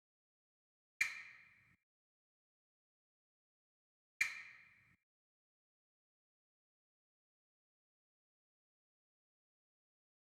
Beast_Percussion.wav